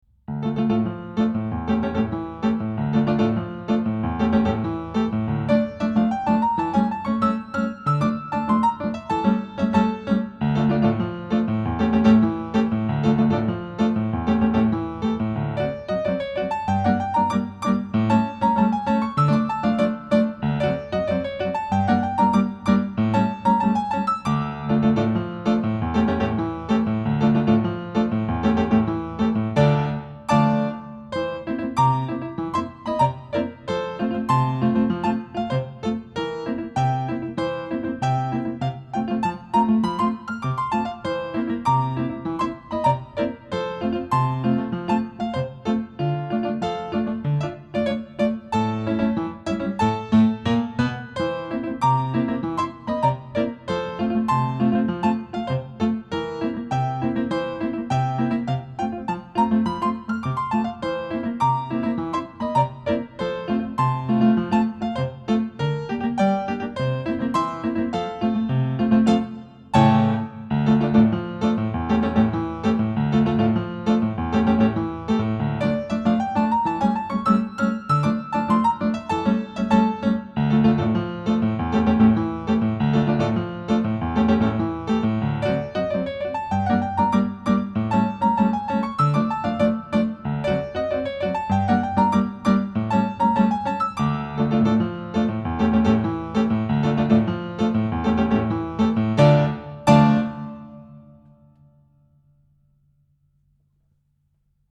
corta_jaca_tango.mp3